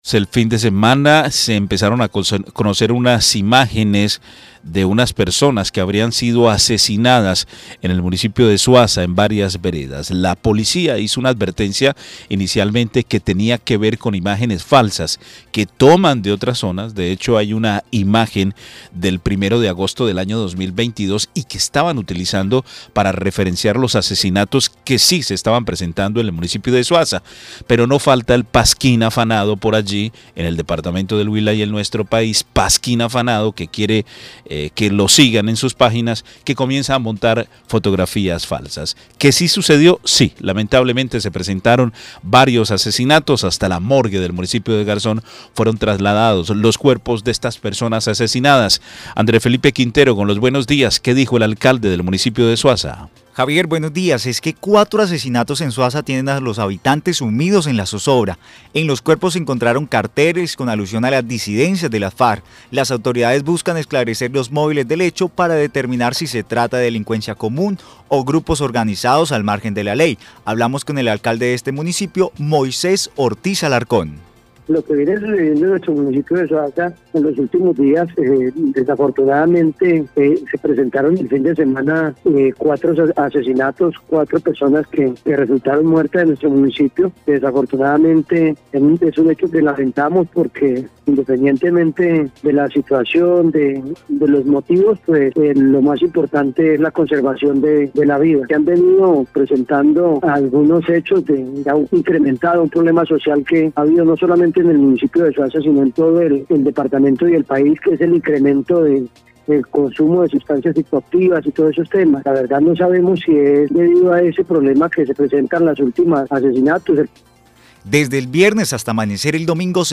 El Alcalde de este municipio Moisés Ortiz Alarcón informó que desde el viernes hasta amanecer el domingo se presentaron los casos que tienen consternados y en intranquilidad a los habitantes de Suaza y municipios Aledaños.